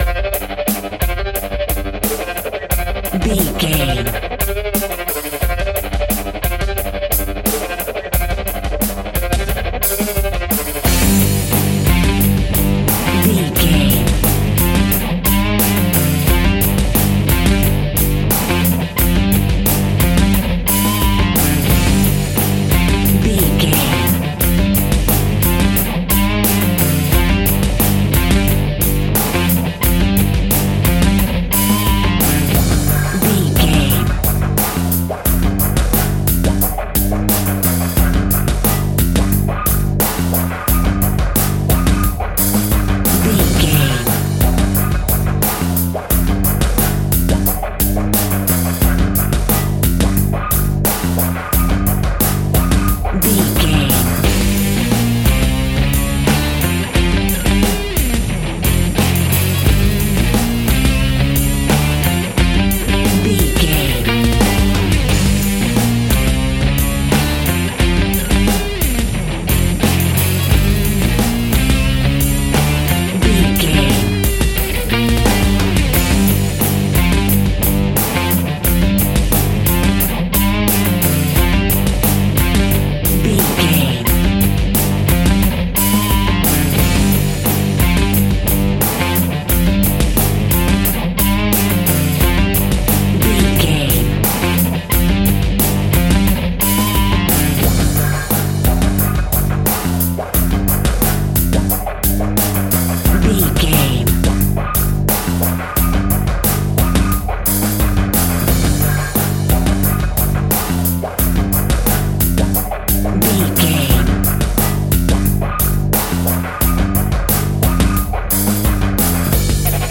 Powerful Rock Music Track Full Mix.
Aeolian/Minor
heavy metal
blues rock
instrumentals
Rock Bass
heavy drums
distorted guitars
hammond organ